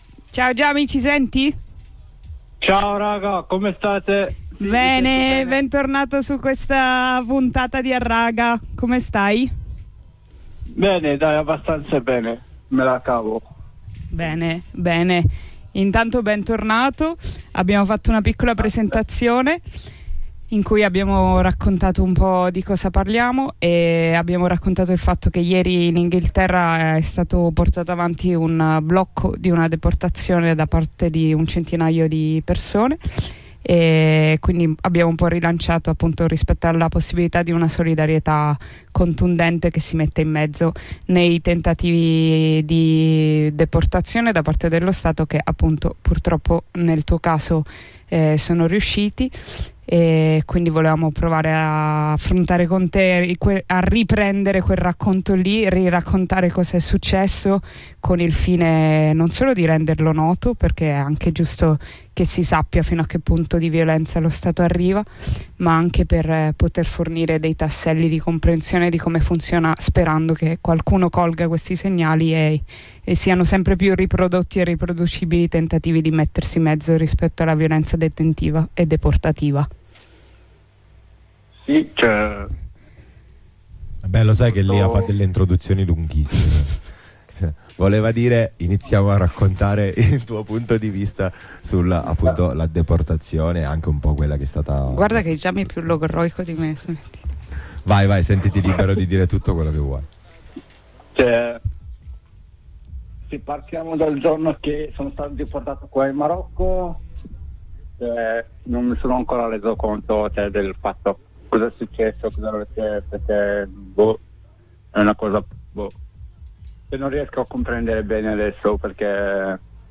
Ai microfoni di Harraga, in onda su Radio Blackout il venerdì dalle 14:30 alle 16, una lunga ed emozionante diretta con un compagno deportato in Marocco durante il mese di Marzo ci ricorda cosa vuol dire resistere alla violenza dello Stato, giorno dopo giorno con ogni pezzo di cuore e di corpo.